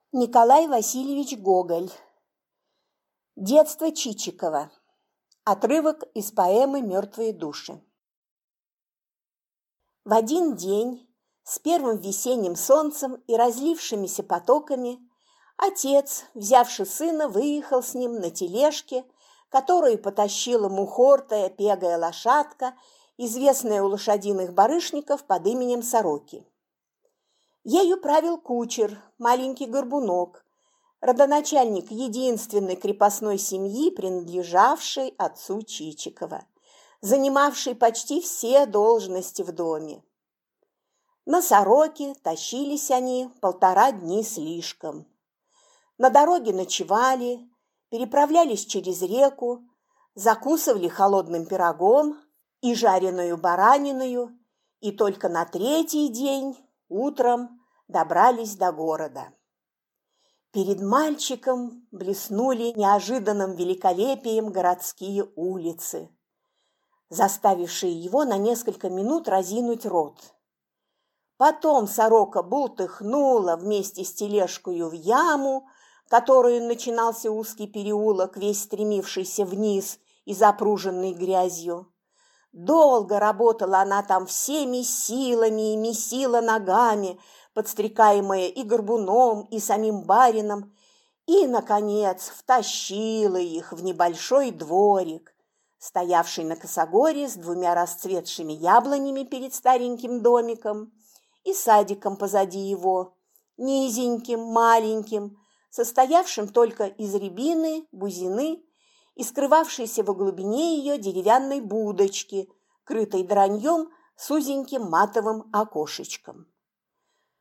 Аудиокнига Детство Чичикова (отрывок из поэмы «Мертвые души») | Библиотека аудиокниг